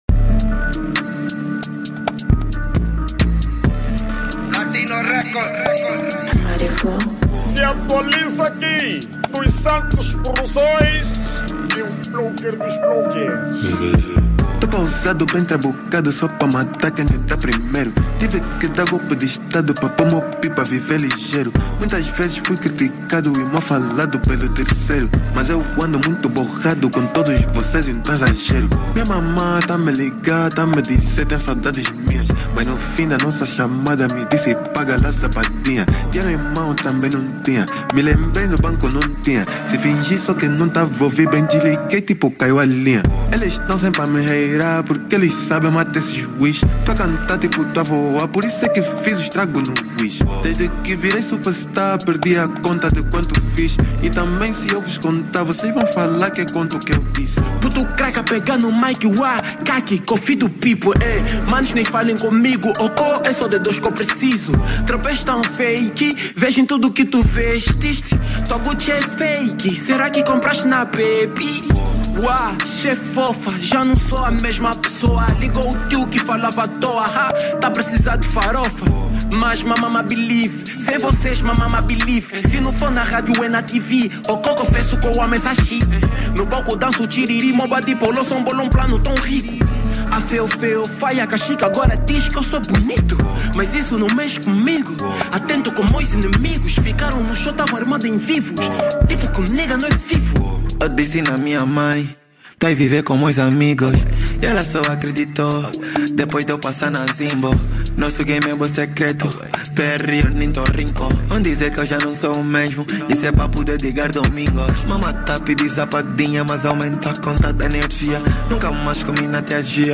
Categoria: Rap